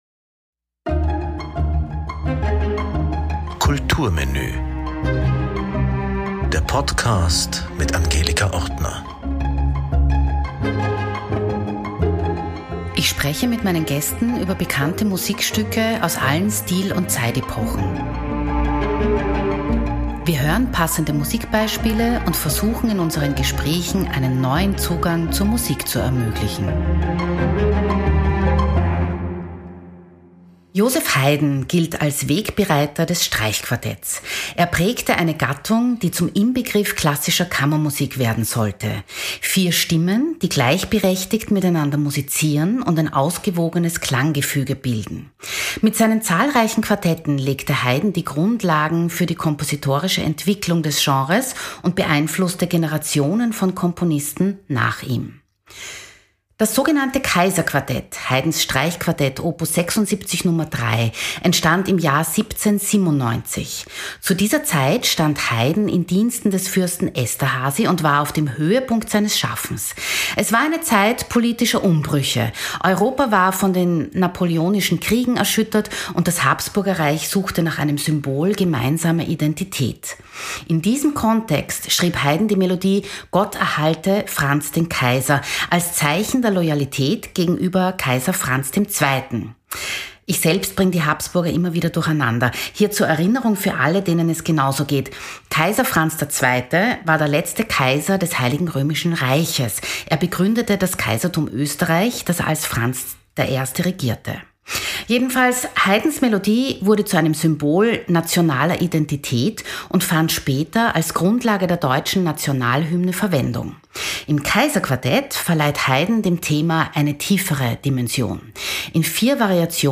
Im Gespräch
Wir hören neben Aufnahmen dieses einzigartigen Quartetts auch „Coverversionen“ von Bedřich Smetana, Johann Strauss Sohn und Clara Schumann.